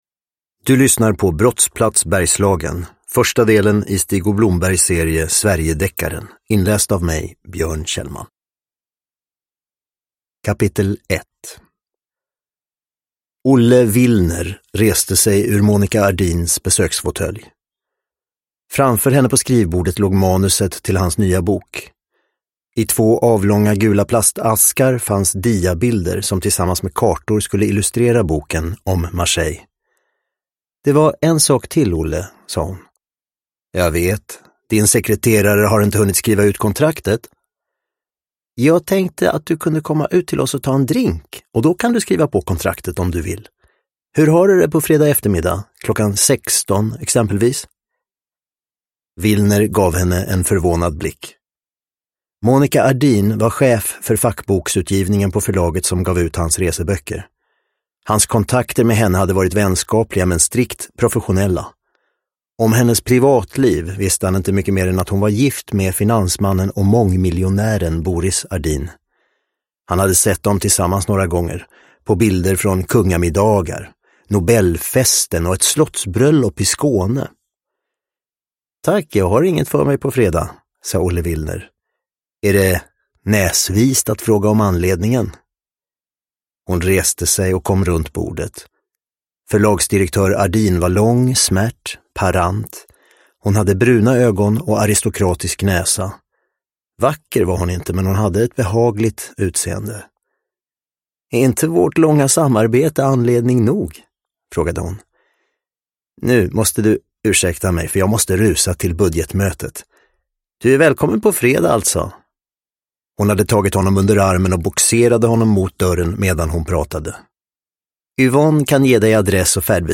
Brottsplats Bergslagen – Ljudbok
Uppläsare: Björn Kjellman